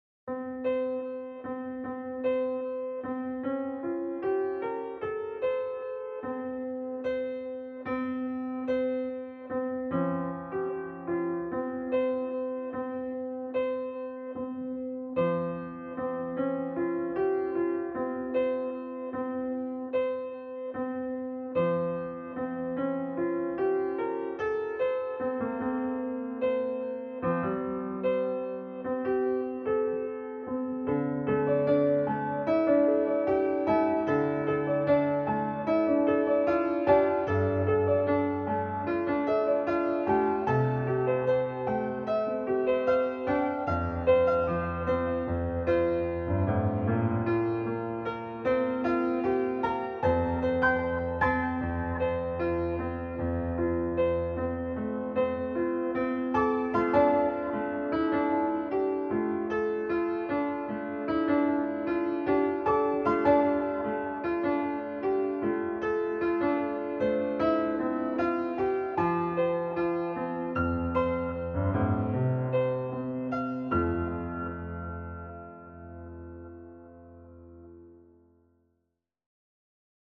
melodieux - piano - aerien - pop